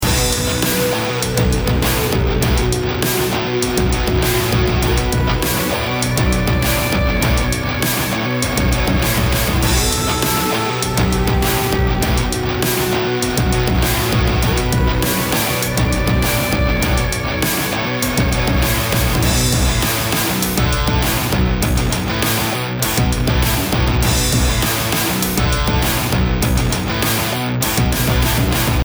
BPM 100